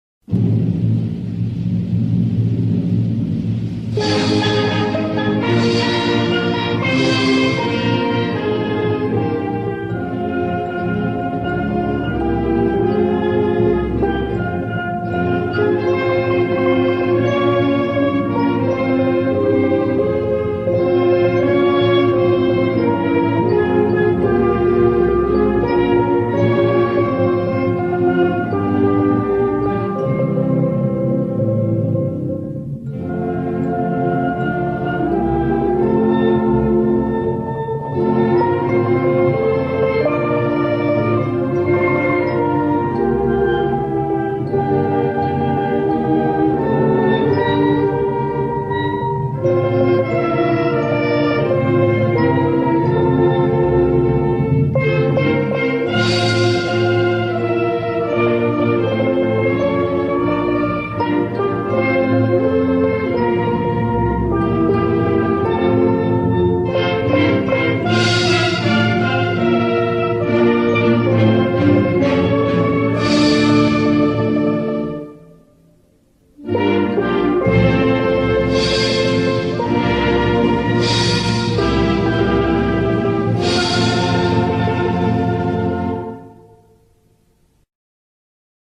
Trinidadian_anthem.mp3